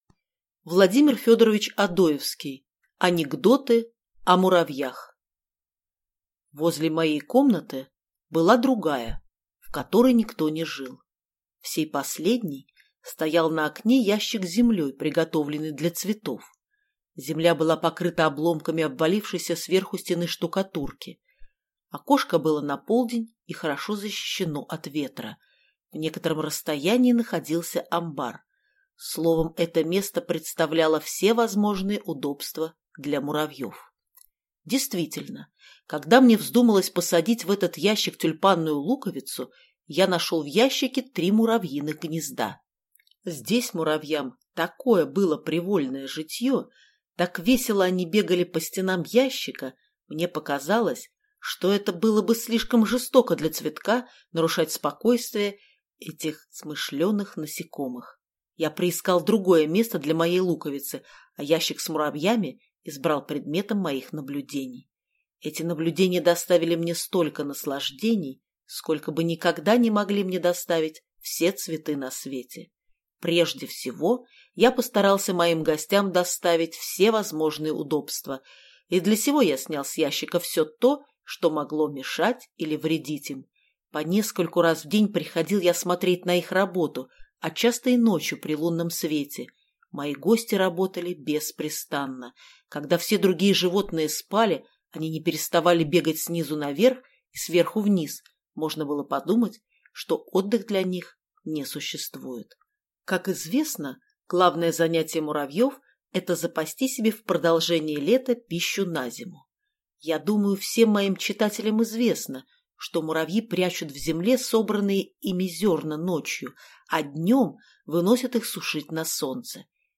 Аудиокнига Анекдоты о муравьях | Библиотека аудиокниг